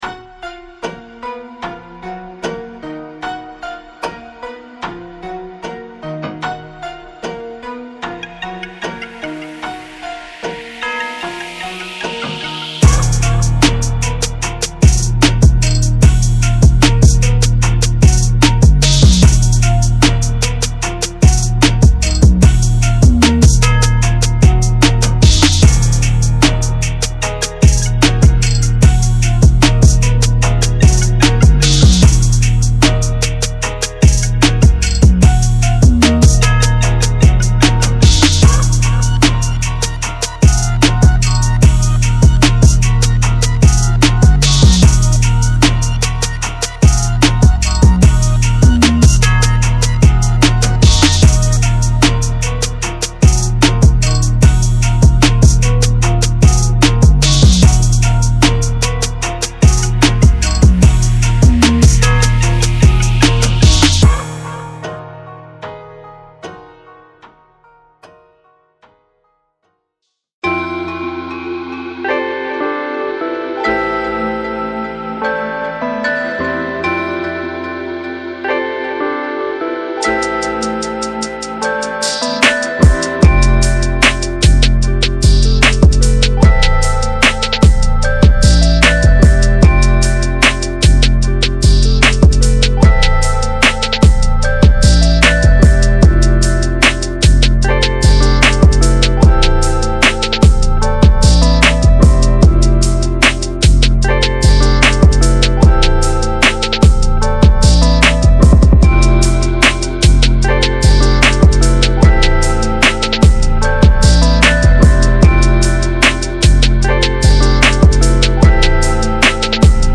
• 风格Trap/Hip-Hop
湿文件包含音频演示中听到的所有效果处理。
• 110-150 BPM